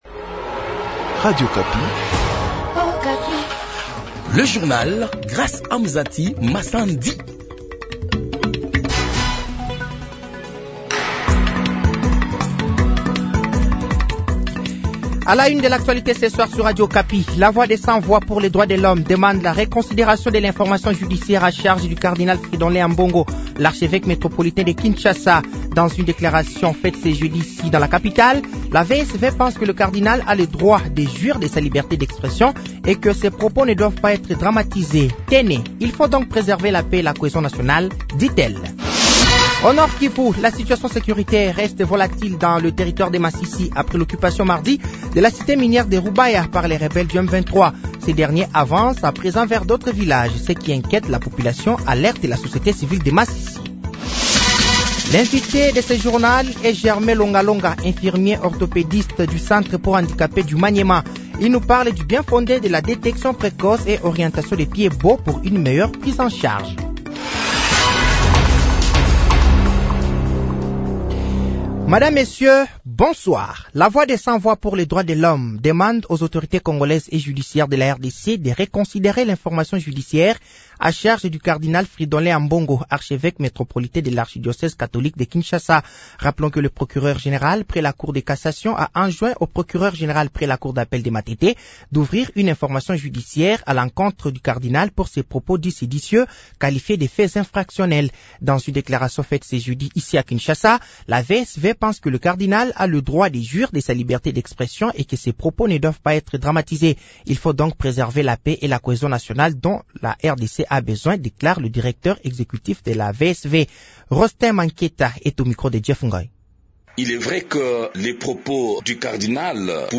Journal français de 18h de ce jeudi 02 mai 2024